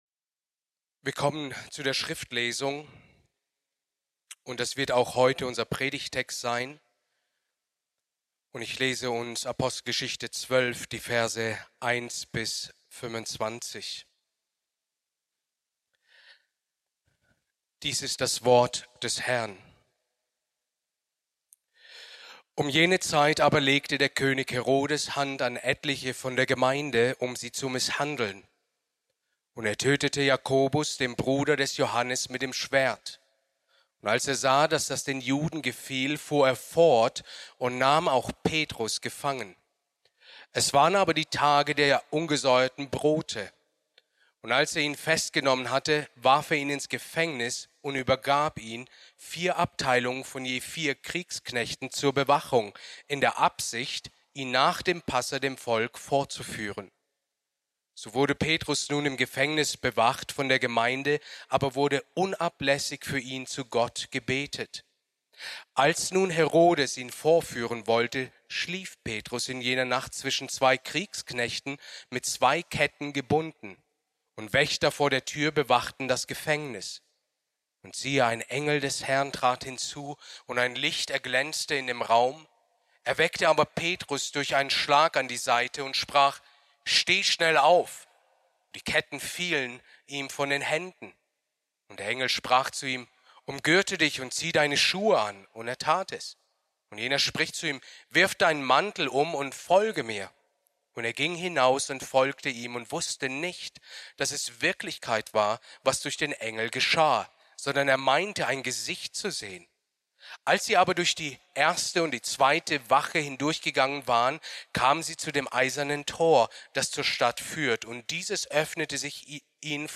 Predigt aus der Serie: "Weitere Predigten"